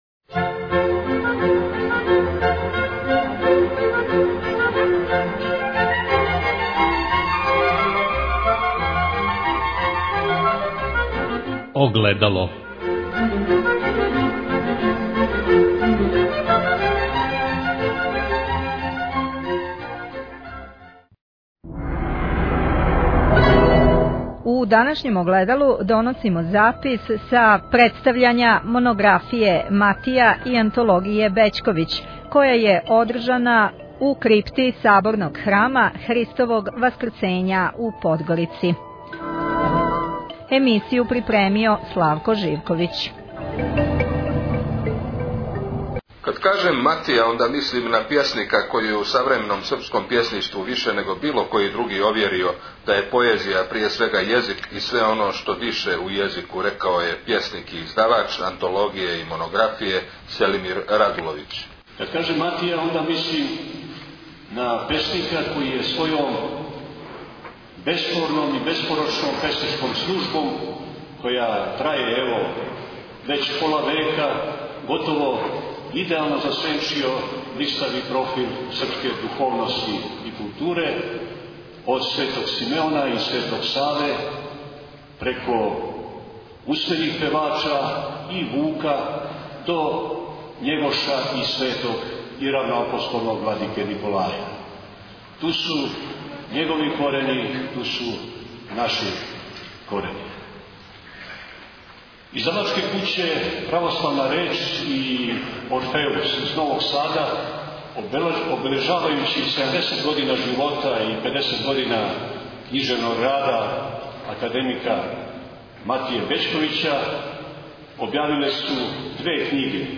Промоција Монографије Матија и Антологије Бећковић у Подгорици Tagged: Огледало Download the file . 81:49 минута (11.72 МБ) Запис са промоције Монографије Матија и Антологије Бећковић одржане у крипти Саборног храма Христовог Васкрсења у Подгорици.